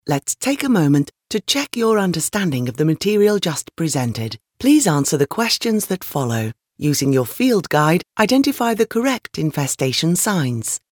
Experienced Female British Voice Over, Fast Turnaround, Professional Service
Sprechprobe: eLearning (Muttersprache):